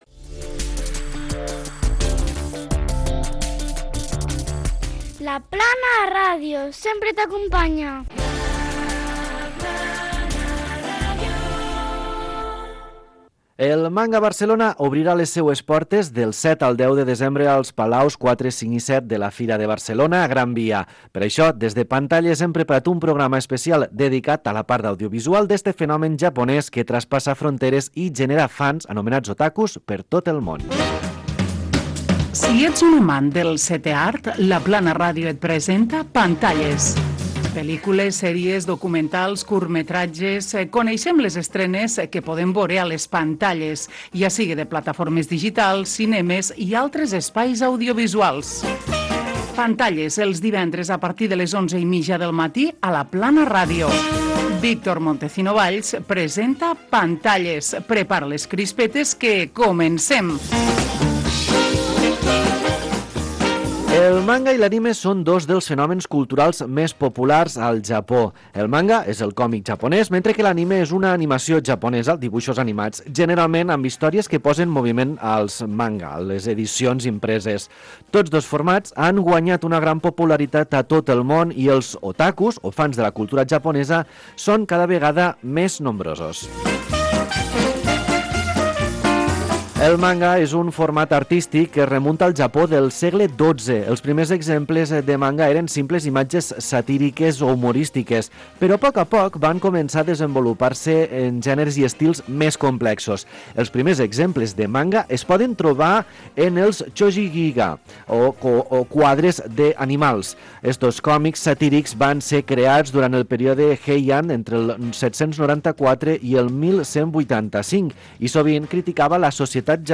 Indicatiu de la ràdio, esment al saló del Manga, careta del programa, explicació sobre el "manga" i l'anime", algunes sèries d'"anime"